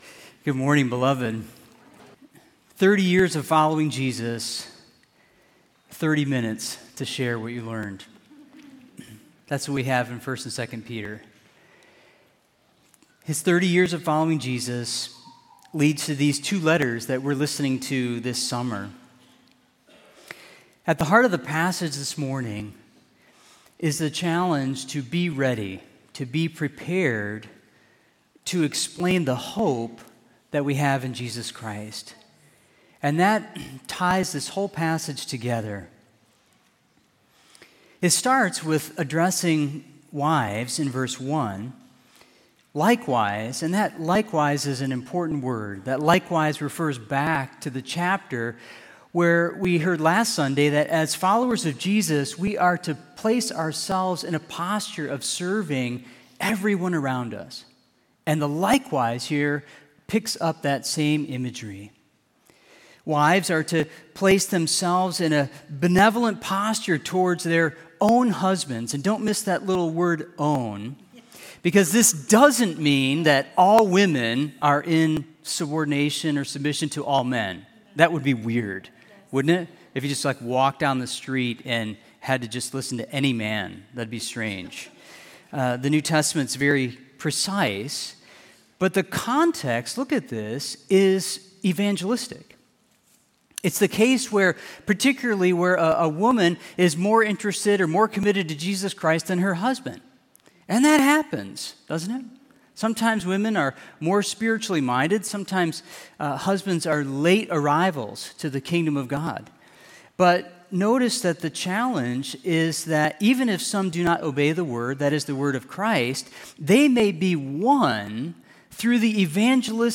Kenwood Baptist Church, Cincinnati, Ohio